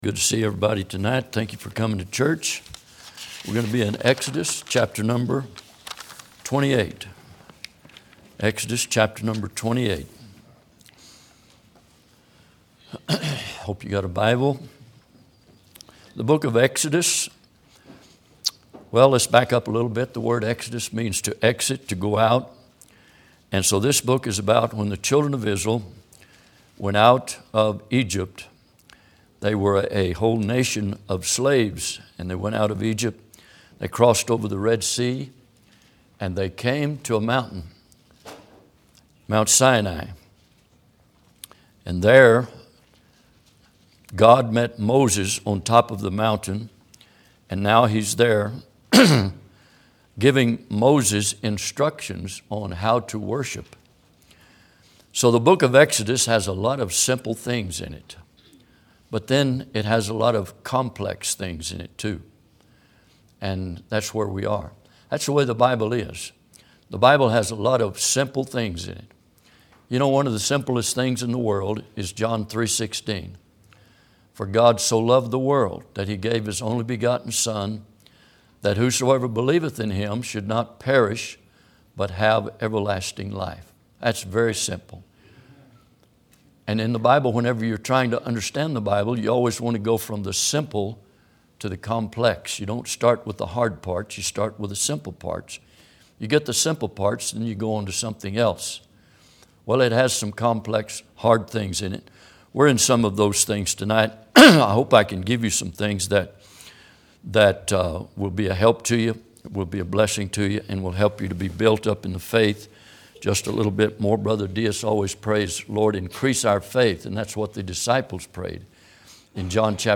Exodus 28:1-10 Service Type: Midweek Bible Text